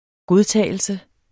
Udtale [ ˈgoðˌtæˀjəlsə ]